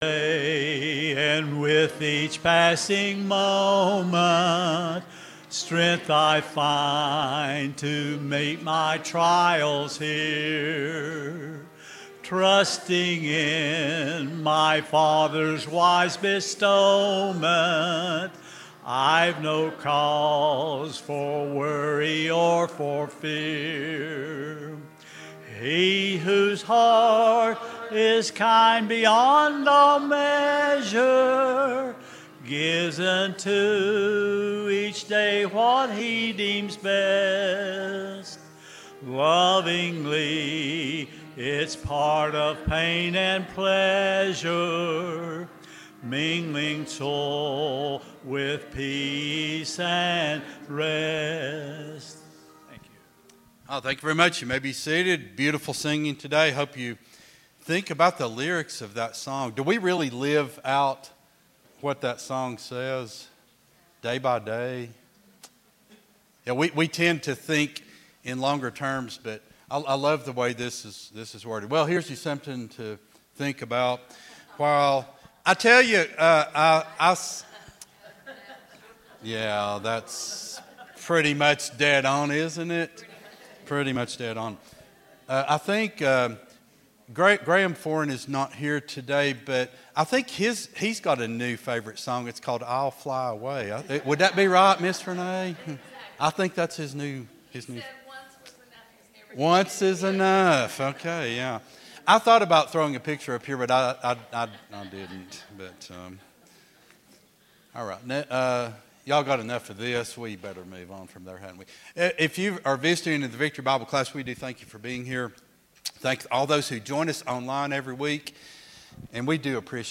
Sunday School Lesson